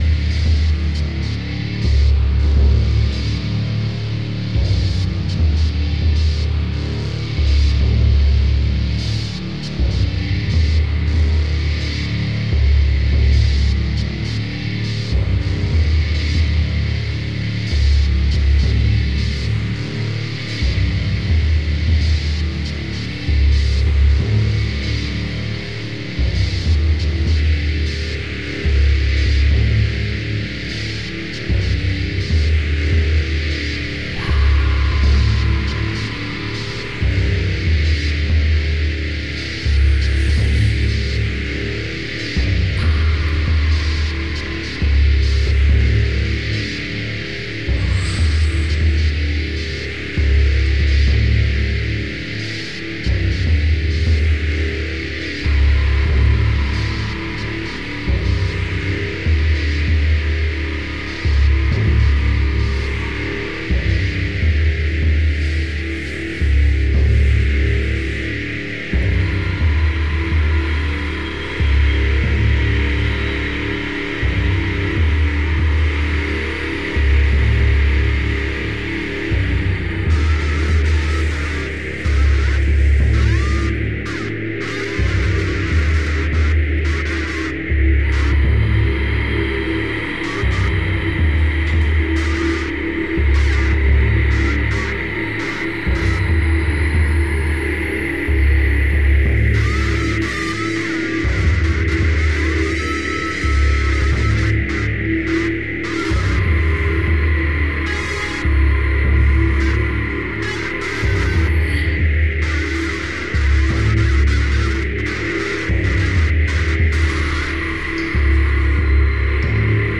The music unfolds in a rough and deep manner
treated guitars and spinet
percussion
• Genre: Experimental / Avant-Garde / Drone